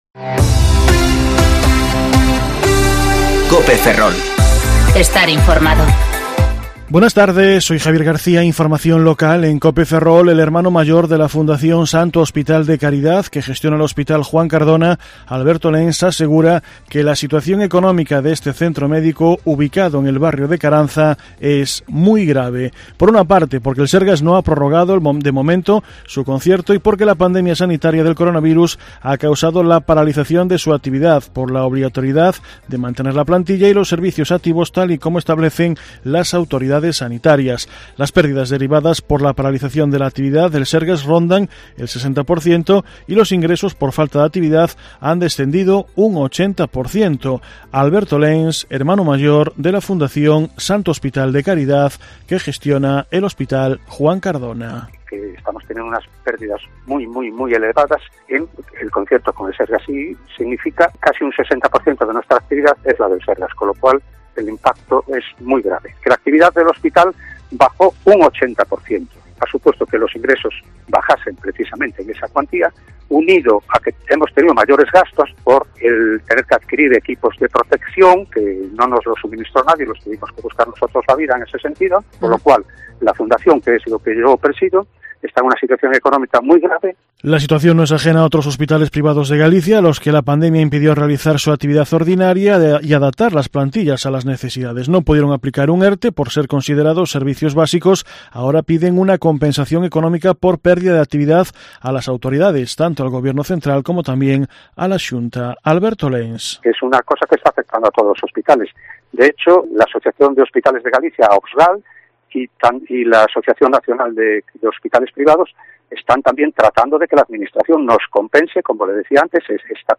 Informativo Mediodía COPE Ferrol 29/06/2020 (De 14,20 a 14,30 horas)